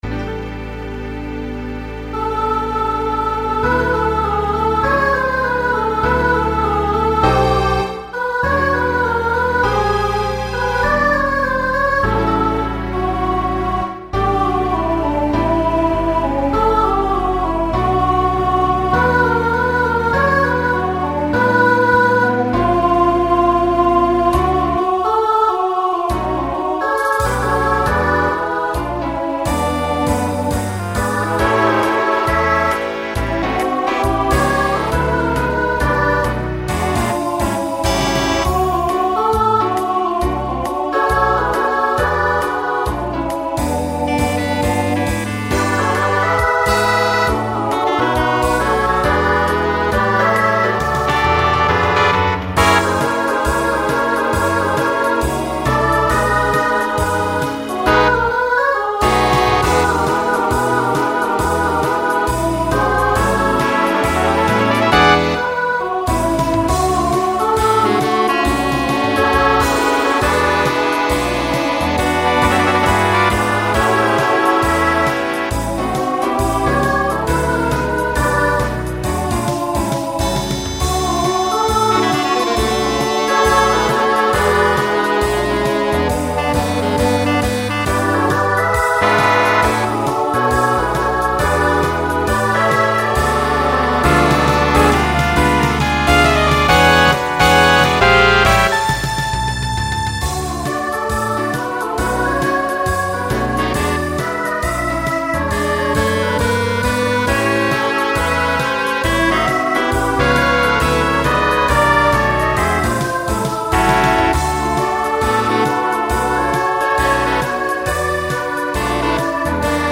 Voicing SSA Instrumental combo Genre Broadway/Film
Mid-tempo